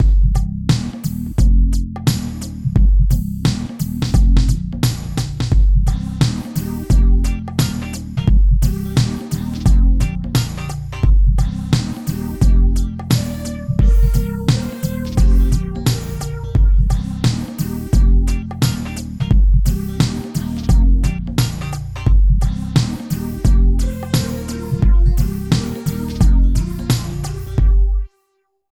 27 LOOP   -L.wav